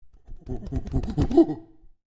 Звуки обезьяны
• Качество: высокое